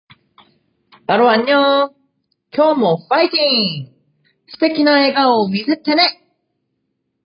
【ご注意】 ・掲載（ダウンロード）期間 2025年11月19日～2025年12月26日 ・ダウンロードした音声メッセージは、個人で楽しむ範囲に限ってご利用ください。